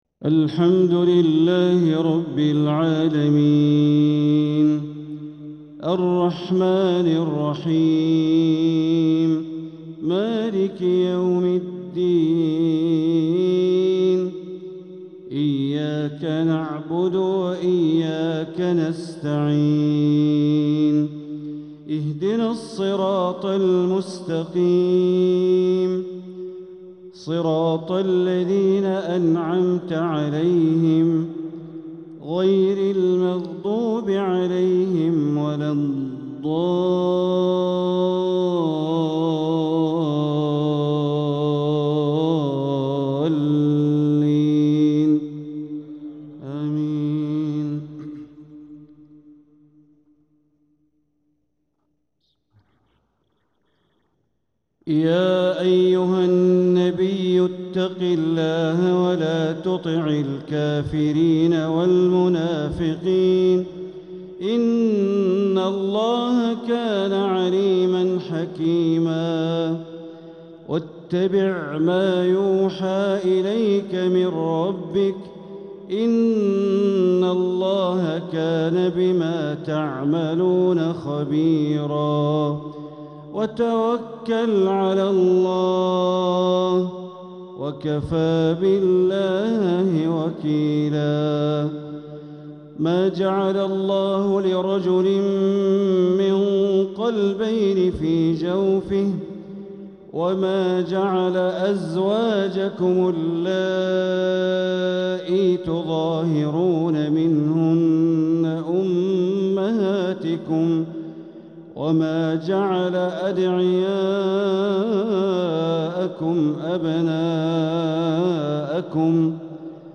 تلاوة من سورة الأحزاب ١-٨ | عشاء الخميس ٣ ربيع الآخر ١٤٤٧ > 1447هـ > الفروض - تلاوات بندر بليلة